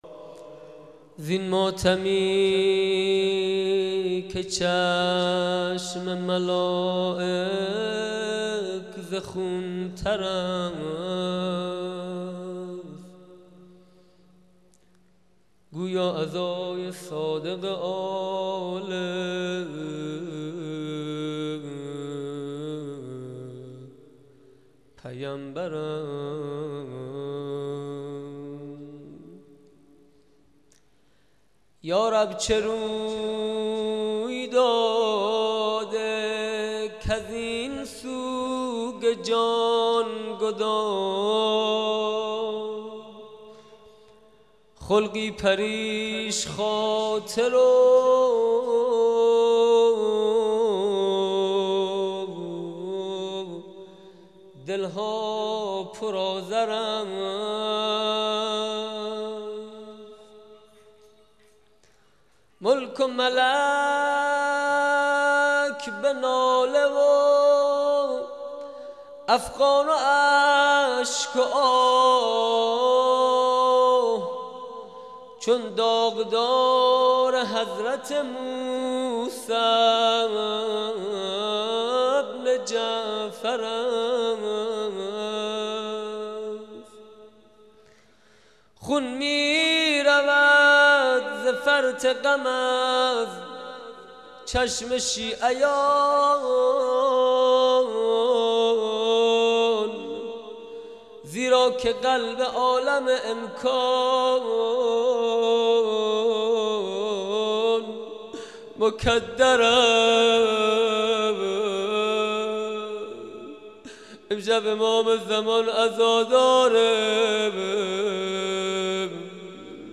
روضه شهادت امام صادق